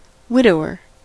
Ääntäminen
US : IPA : [ˈwɪ.do.ʊɚ]